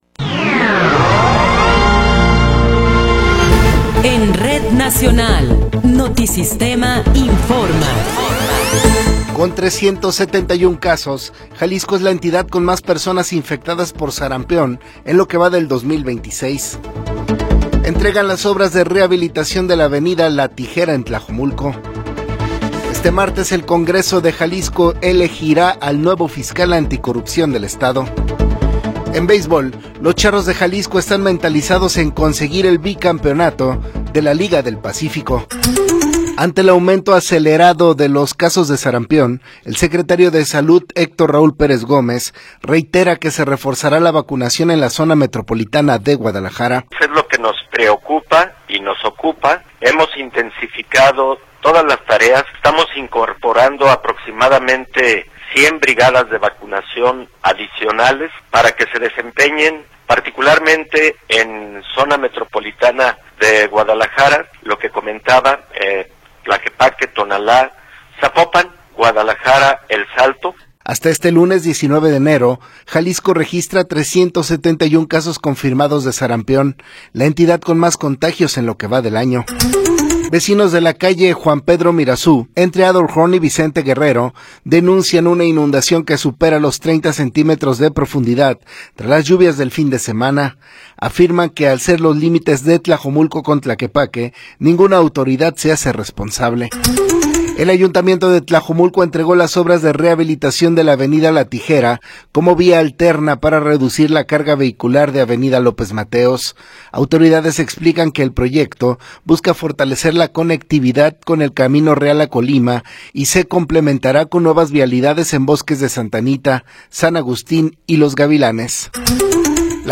Noticiero 9 hrs. – 20 de Enero de 2026